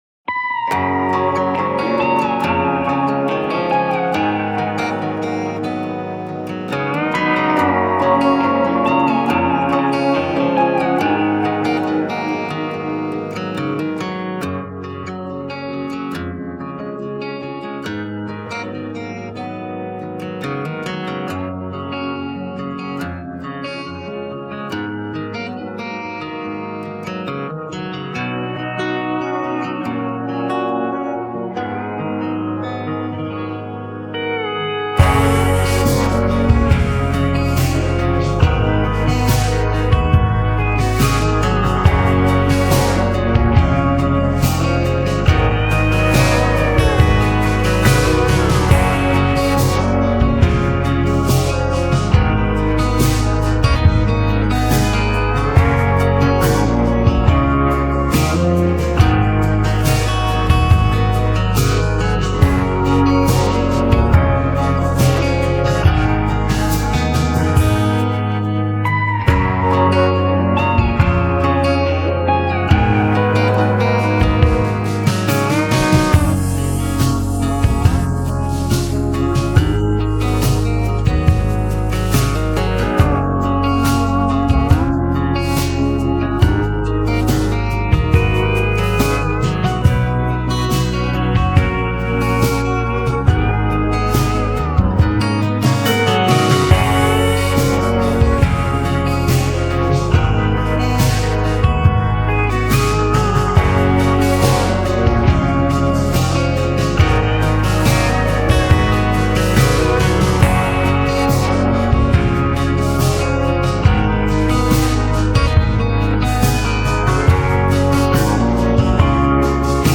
this is the instrumental for it